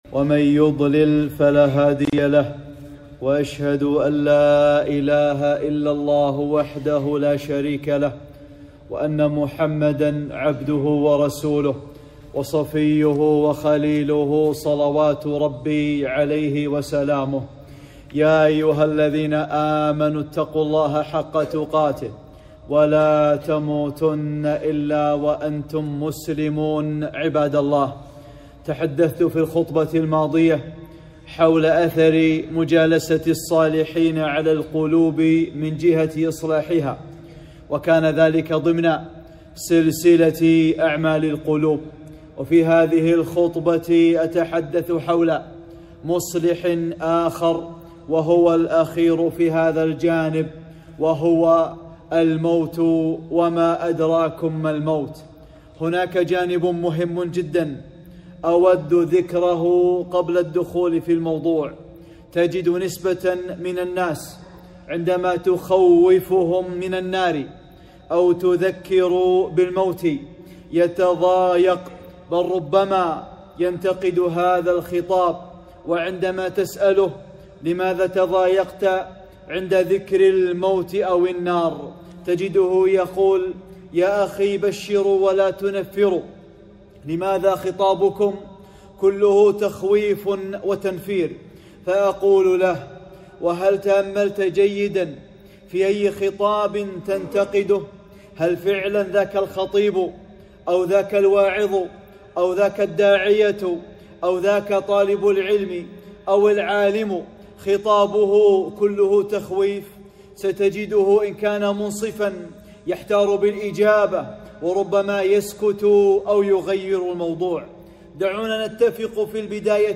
خطبة - (6) الموت وزيارة القبور | أعمال القلوب